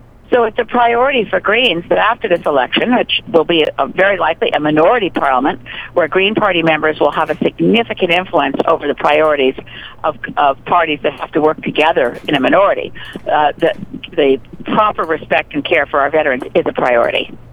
Speaking in Nanaimo today recently, Green Party Leader Elizabeth May said Canada must do more to support veterans.